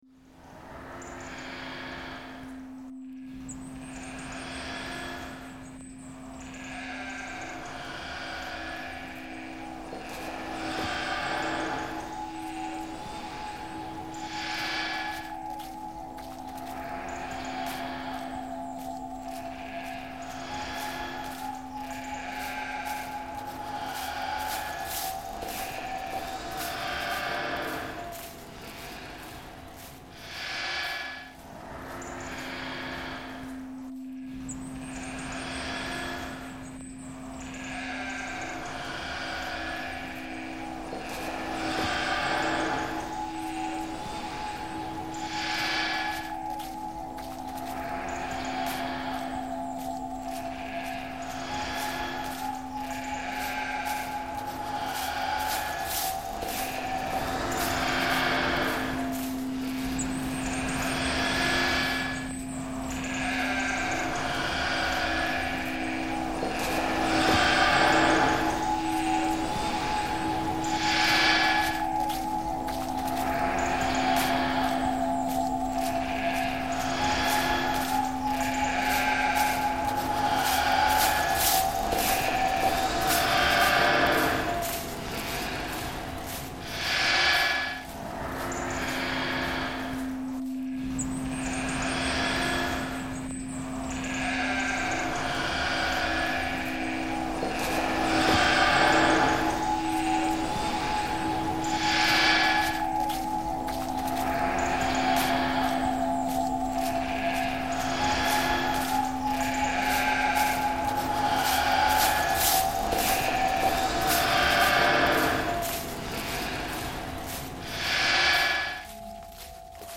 Valldemossa, Mallorca soundscape reimagined